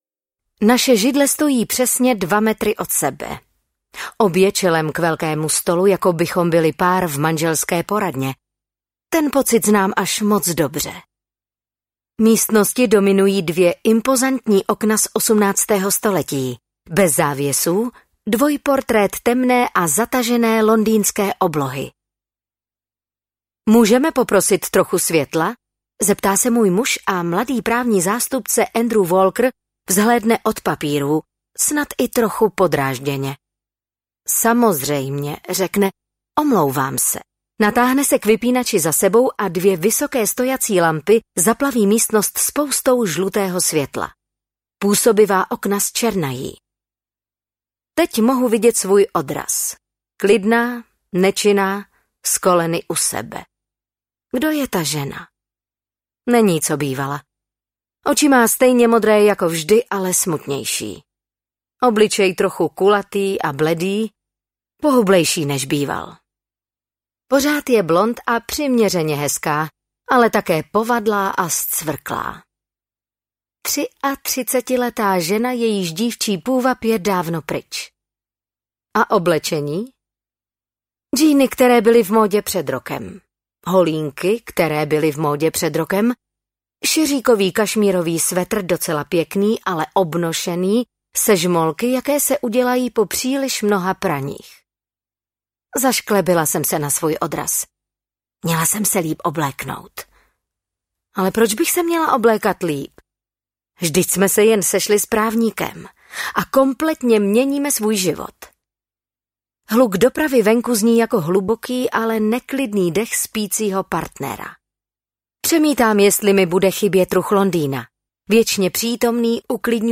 Ledové sestry audiokniha
Ukázka z knihy
• InterpretRegina Řandová
ledove-sestry-audiokniha